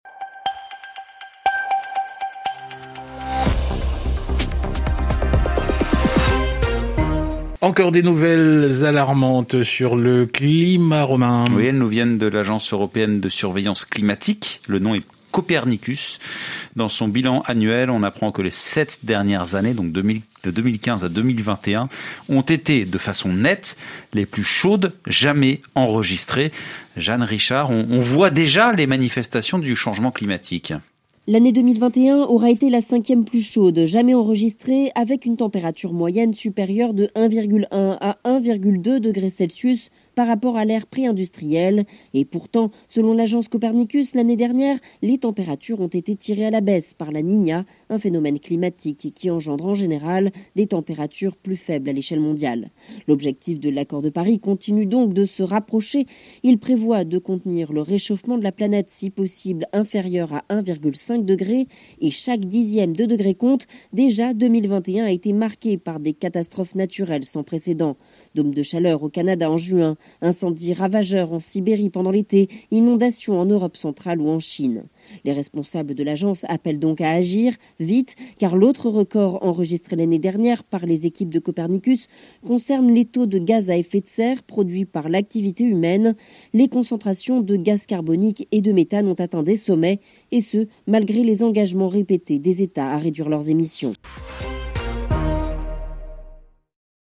Extrait du Journal en français facile du 11/01/2022 (RFI)